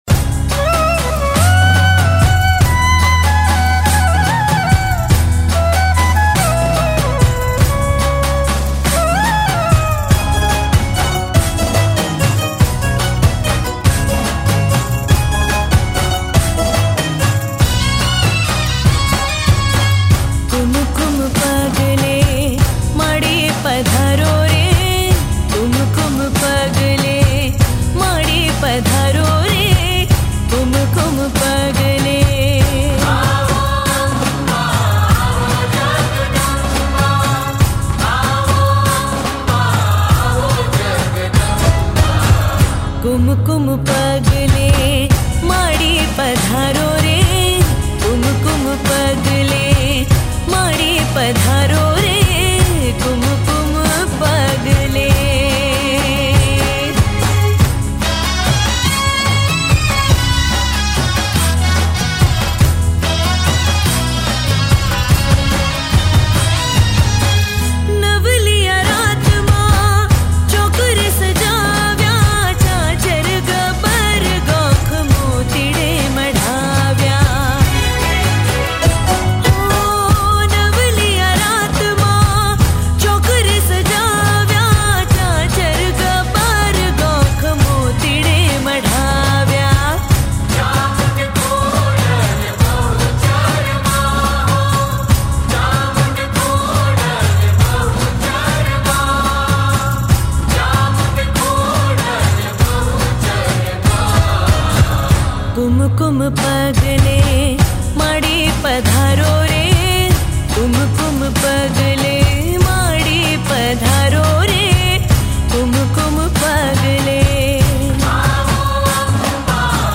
Navratri Garba Albums